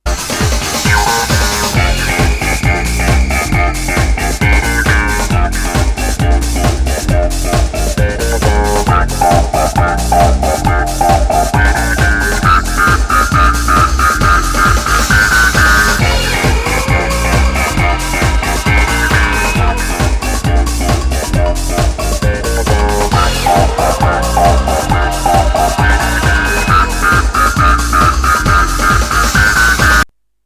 Breakbeat / Progressive House / Techno Lp Reissue